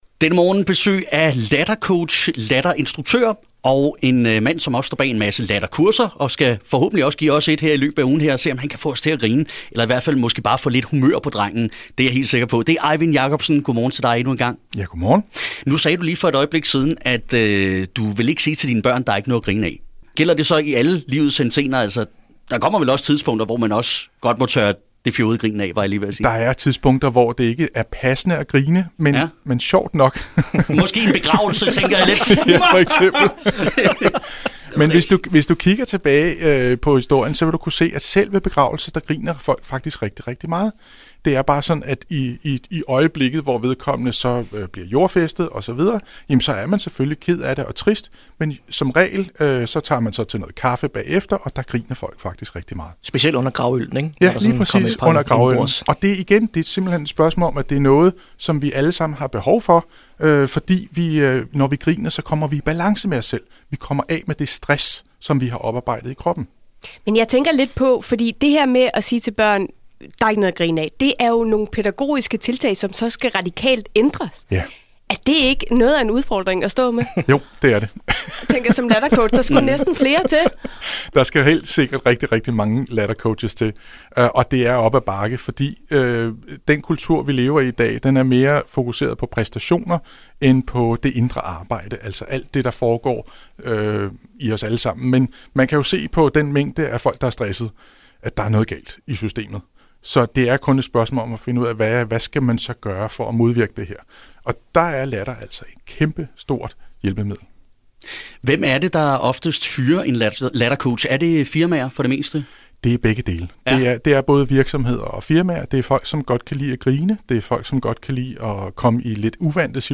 Lattercoach-2.MP3.mp3